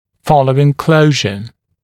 [‘fɔləuɪŋ ‘kləuʒə][‘фолоуин ‘клоужэ]после закрытия (напр. диастемы)